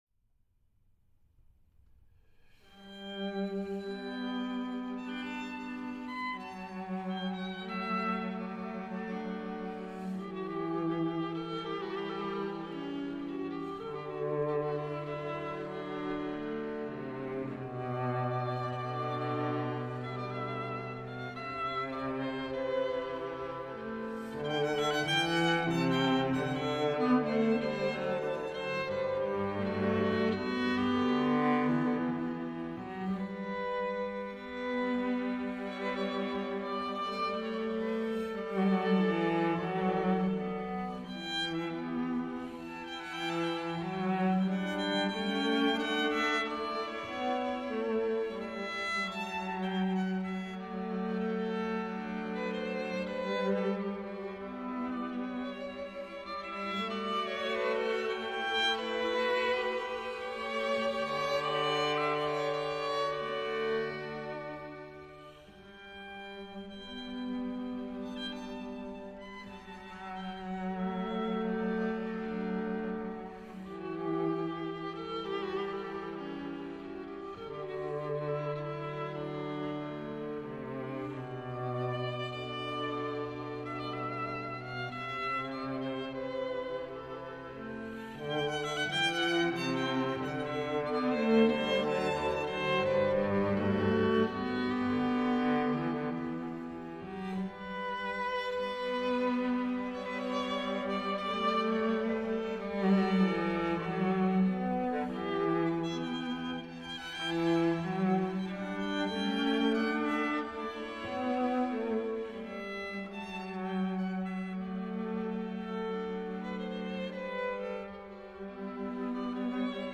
Bach Piano Sonates Violon Violoncelle
• Sonates pour violon et clavecin.
• Les Variations Goldberg, écrites à l’origine pour un insomniaque –ça tombe bien !-, sont ici livrées dans une jolie version pour instruments à cordes –violon, alto, violoncelle– enregistrée en 2006, dans une transcription réalisée par le violoniste et chef d’orchestre Dmitry Sitkovetsky.